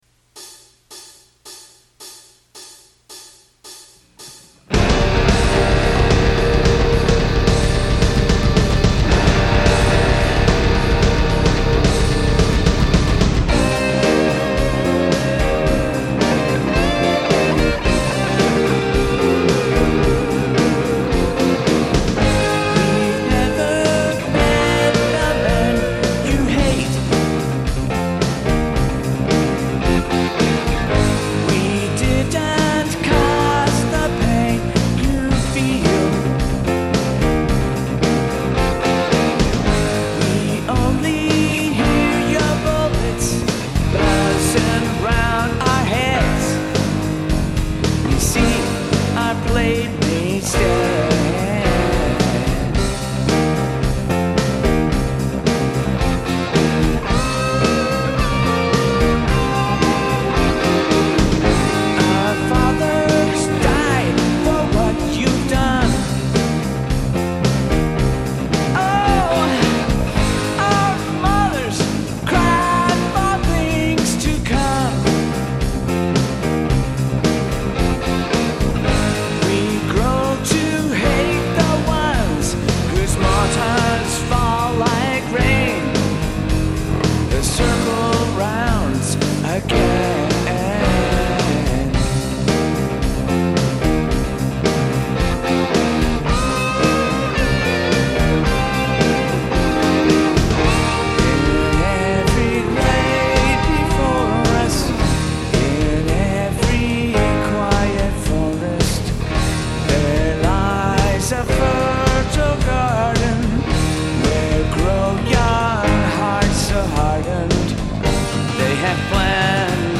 1st backing guitar and piano.